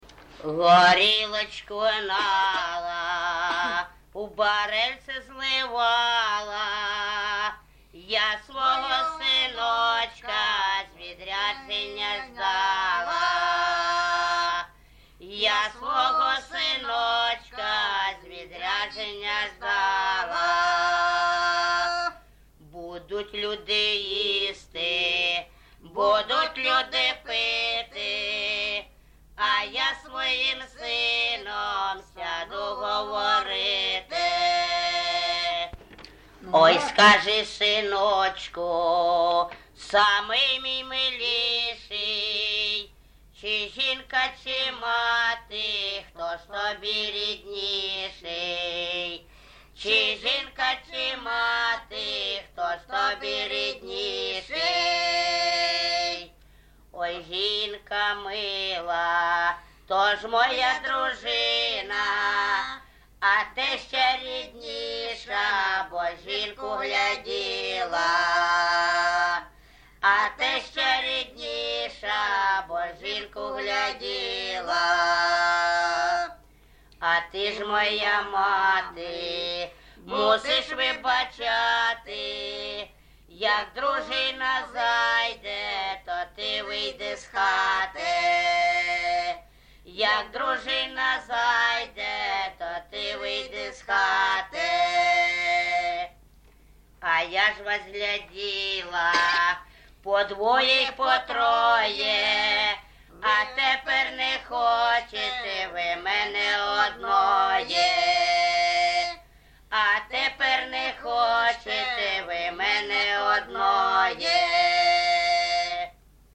ЖанрПісні з особистого та родинного життя, Сучасні пісні та новотвори
Місце записус. Гарбузівка, Сумський район, Сумська обл., Україна, Слобожанщина